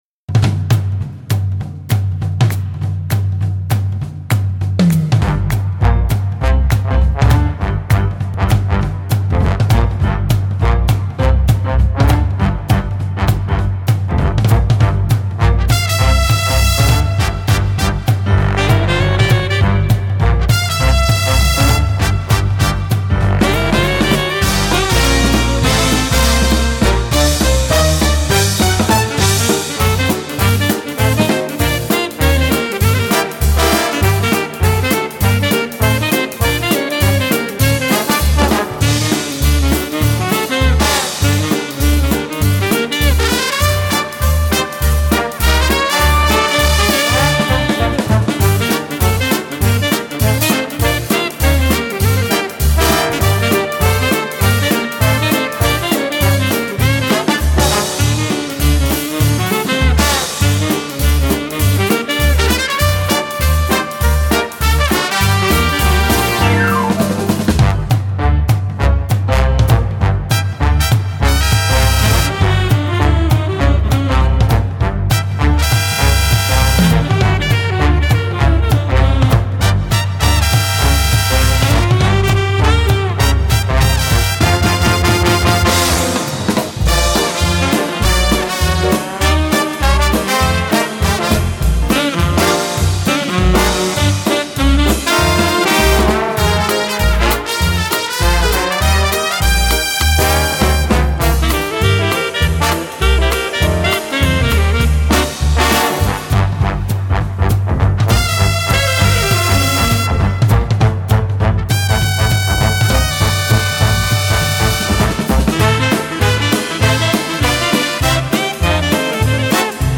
Определить инструментал.
Явно слышатся интонации, намекающие на Sing, Sing, Sing из Бенни Гудмена, но это явно какой-то современный оркестр.